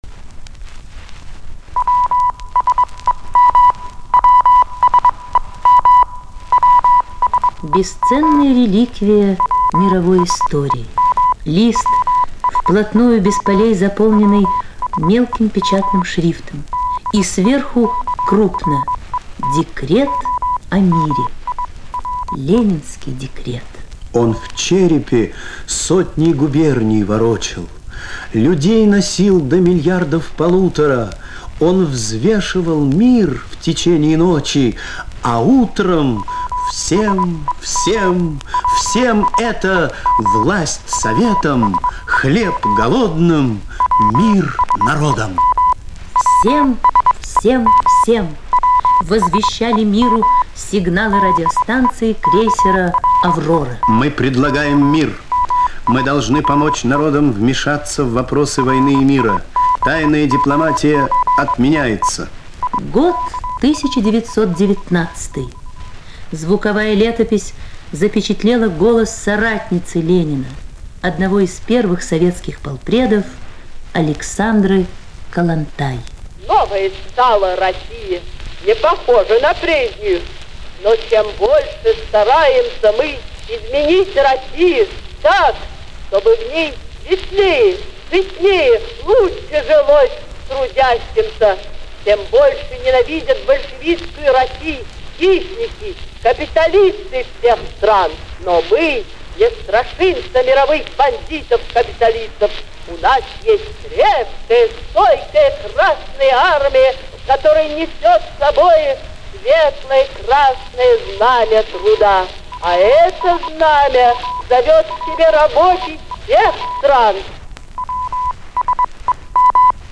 В этом номере мы публикуем звуковые документы из истории внешней политики Советского Союза.
Звуковая страница 1 - Всем, всем, всем! Голоса нашей биографии. Звуковые документы истории внешней политики СССР.